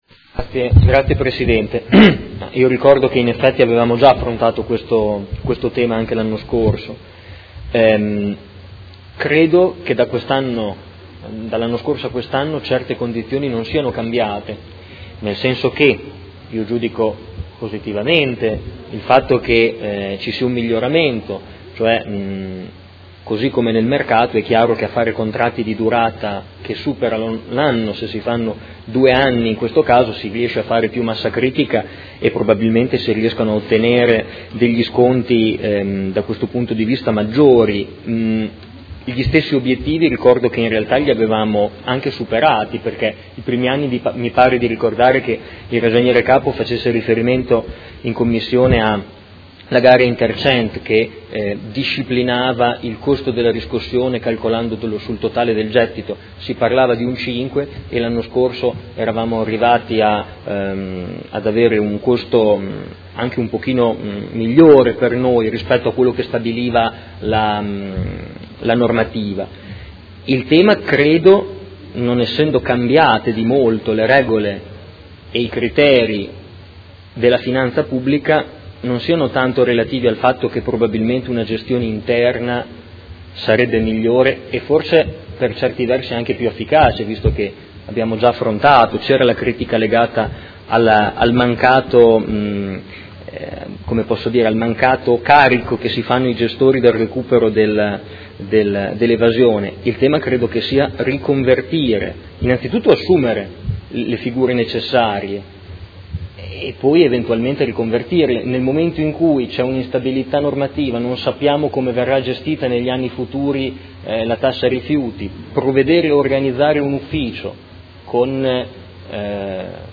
Seduta del 30/03/2017. Dibattito su proposta di deliberazione: Convenzione per l’affidamento della gestione della riscossione del Tributo comunale sui rifiuti TARI anni 2017-2018 e per la regolamentazione della fatturazione e dei pagamenti del servizio di gestione dei rifiuti urbani ed assimilati (SGRUA) anno 2017